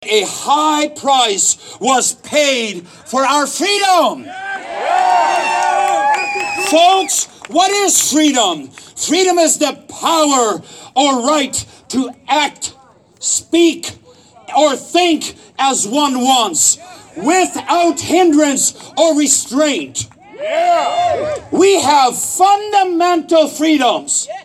The rally opened with a series of speakers – using a pickup truck as a platform – to convey a variety of messages touching on eroding freedoms, their belief the coronavirus is but a myth and the dangers associated with a COVID-19 vaccine.